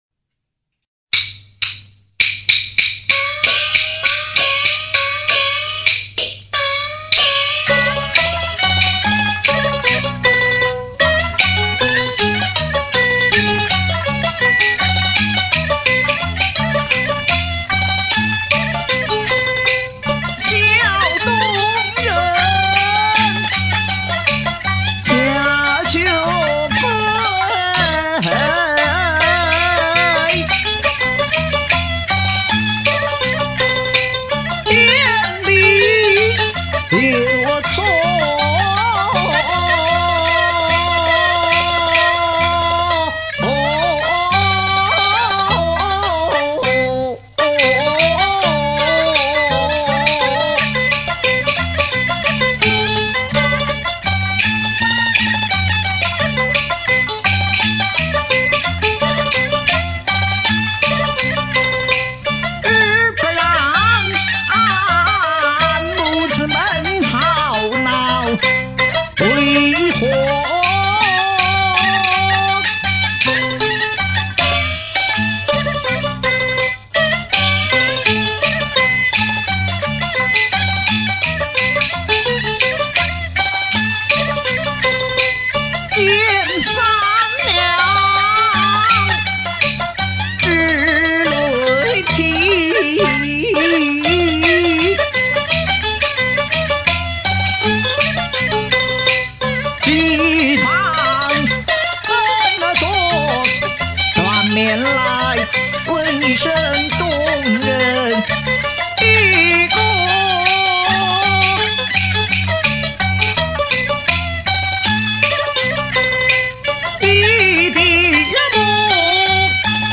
学唱